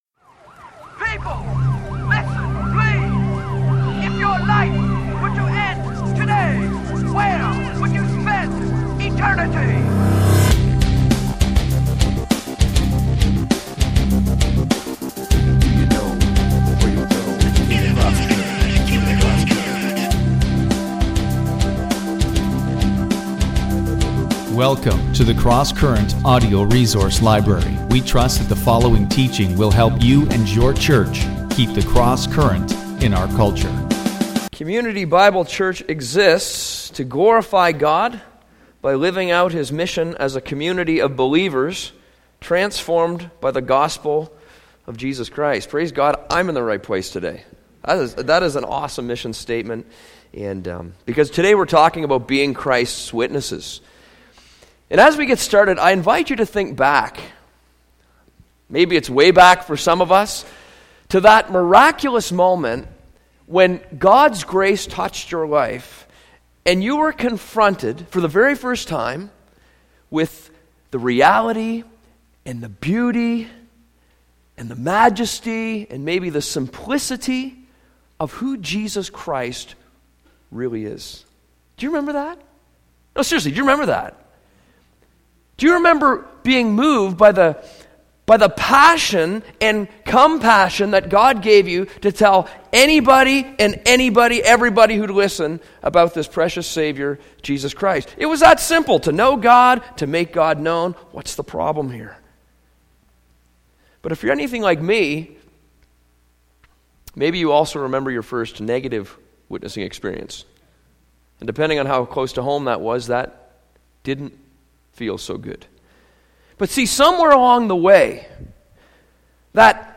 The Gospel: An Enigma of God’s Goodness (Sermon)